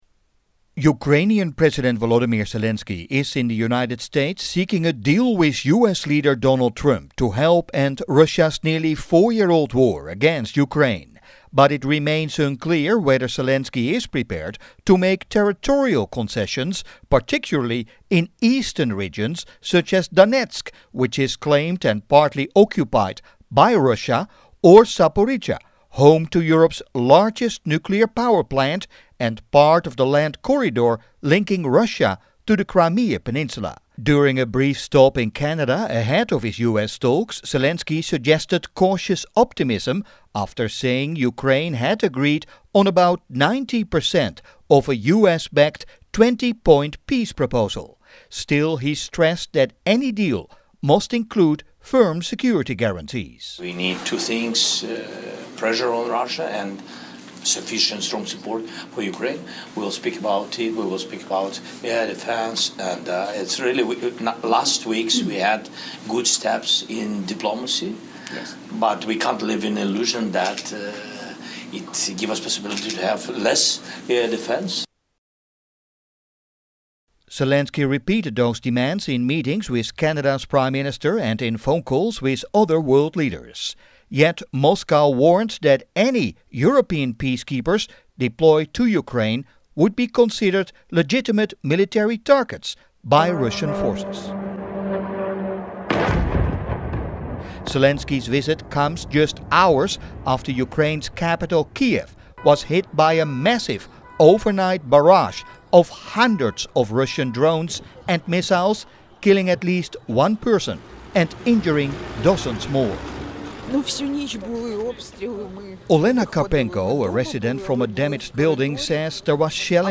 worthynewsradiodec28.mp3